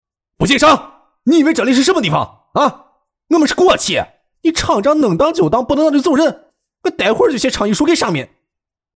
20 男国472_动画_角色_秦主任50岁角色 男国472
男国472_动画_角色_秦主任50岁角色.mp3